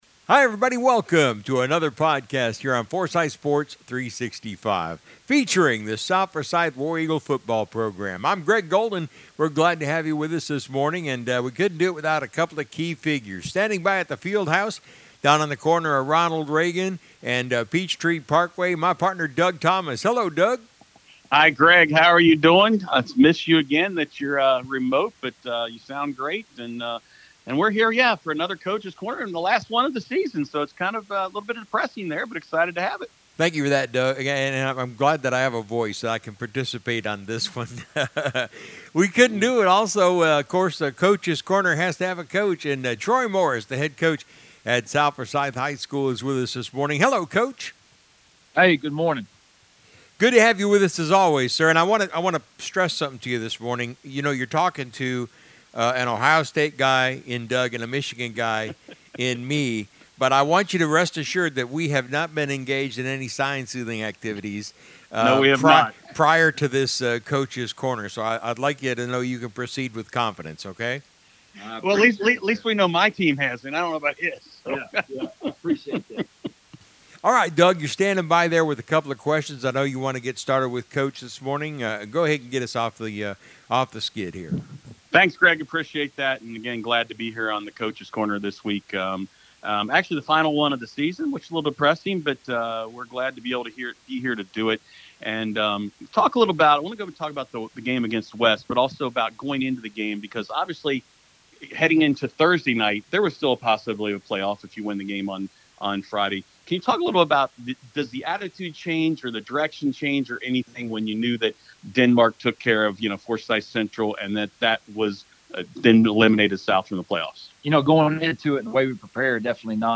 I didn’t explain it in the interview, cause I thought I’d do it here.